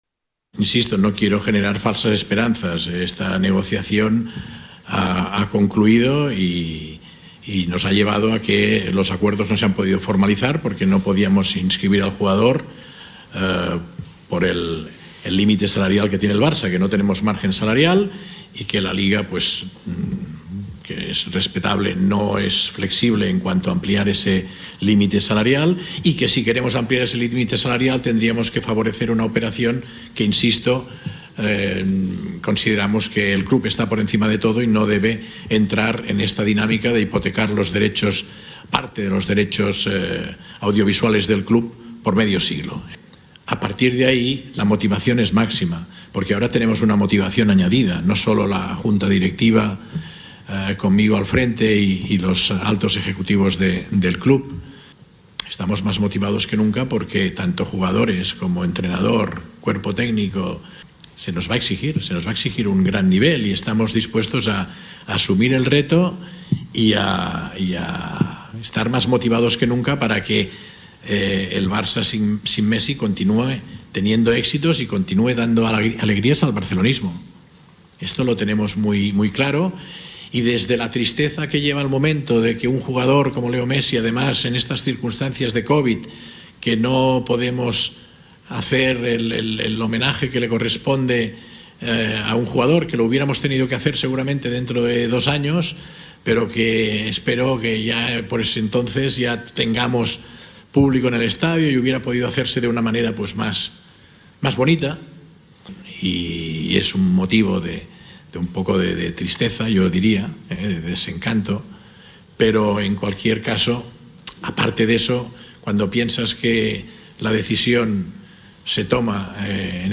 (Joan Laporta, presidente del Barcelona)
La razón por la que Messi no renovó fue porque "no queremos poner más en riesgo la institución (…) Estoy triste, pero creo que hemos hecho lo mejor para los intereses del Barcelona. Ha llegado un momento en el que en una negociación te tienes que plantar, tienes que dejar las emociones fuera”, sostuvo Laporta en rueda de prensa.